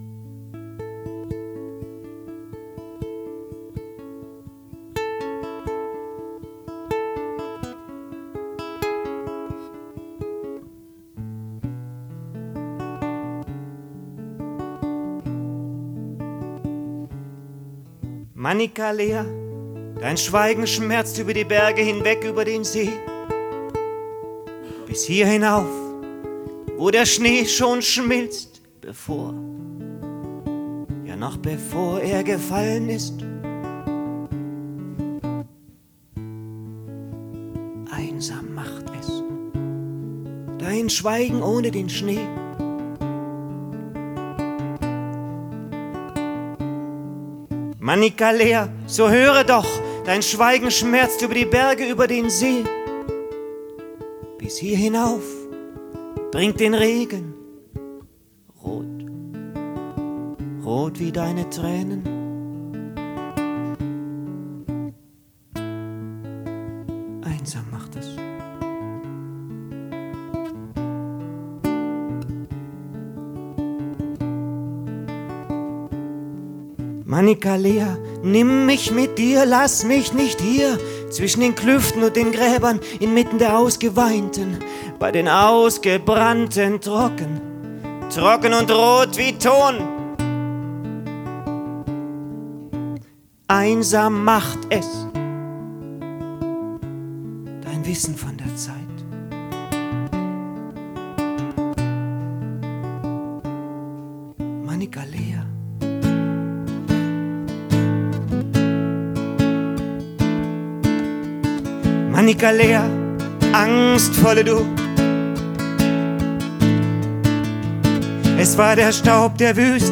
„manicalea“ (live, 04. august 2007)
(aufnahme vom 04. august 2007 im „café medusa“)